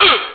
hit_s2.wav